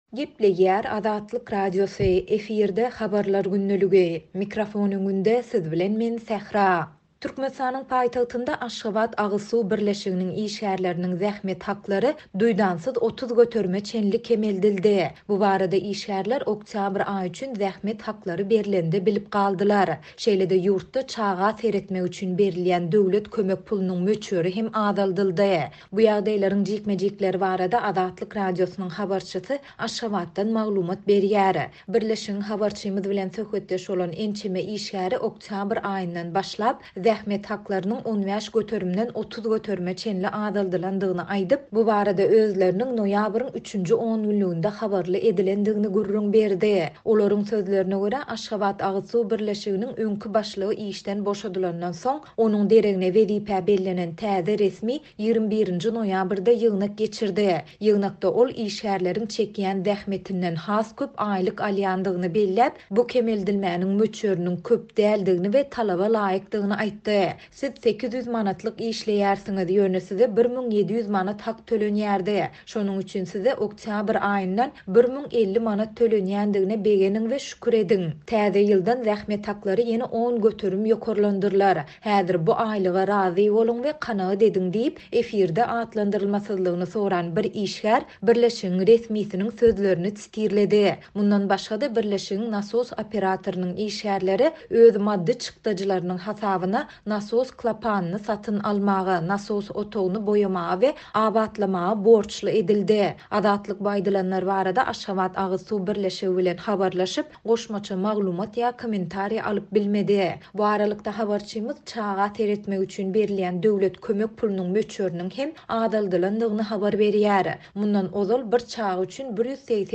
Şeýle-de, ýurtda çaga seretmek üçin berilýän döwlet kömek pulunyň möçberi hem azaldyldy. Bu ýagdaýlaryň jikme-jikleri barada Azatlyk Radiosynyň habarçysy Aşgabatdan maglumat berýär.